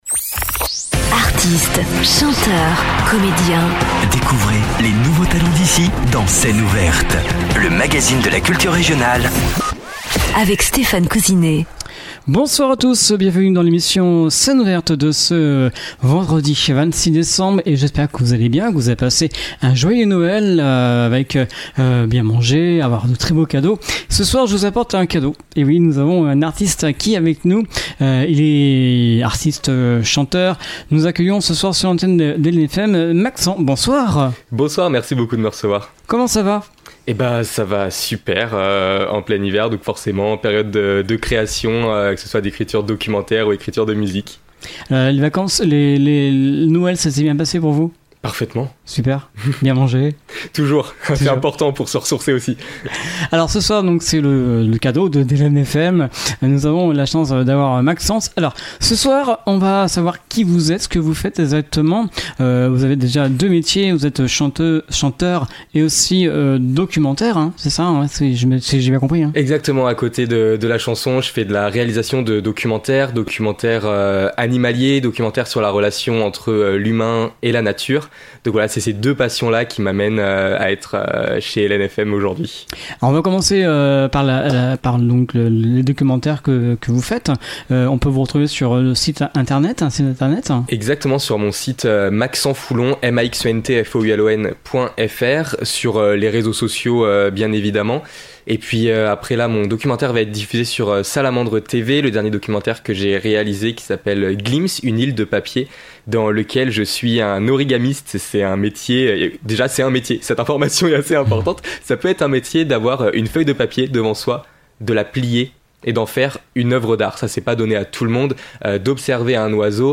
Scène ouverte
Inspiré par la nature et les rencontres, il propose une pop acoustique sensible et sincère, dans la lignée de Ben Mazué ou Vianney.